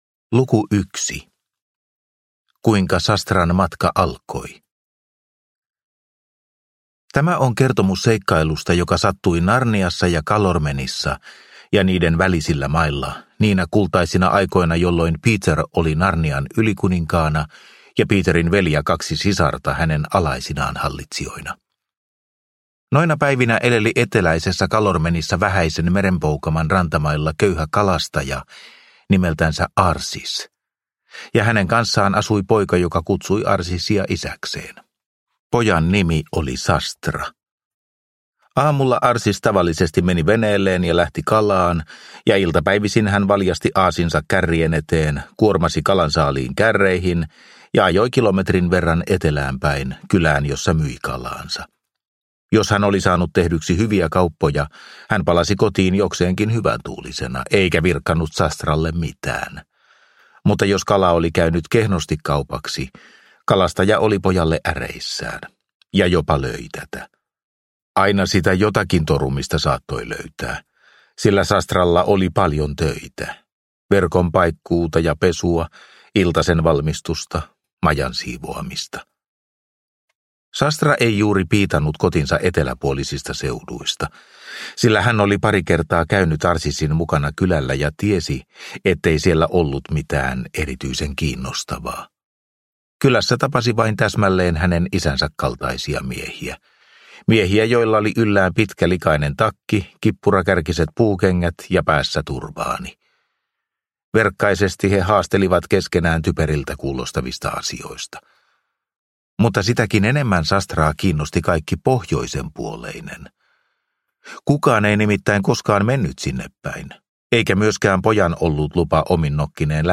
Hevonen ja poika – Ljudbok – Laddas ner